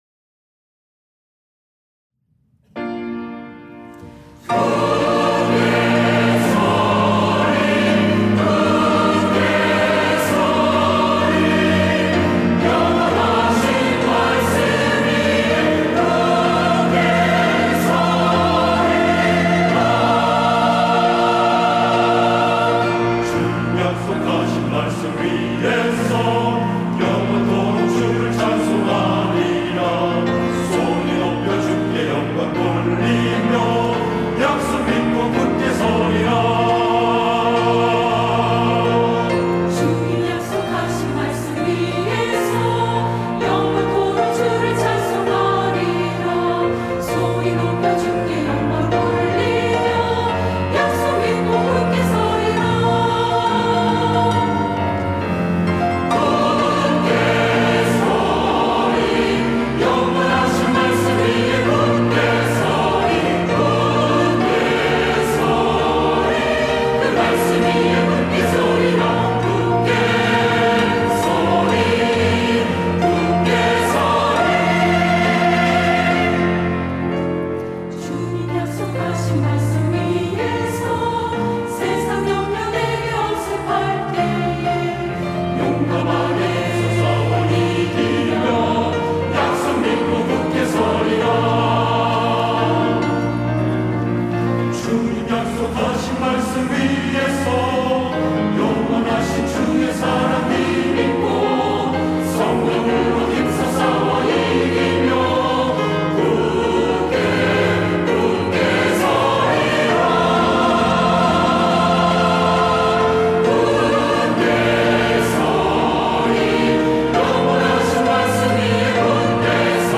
할렐루야(주일2부) - 주님 약속하신 말씀 위에서
찬양대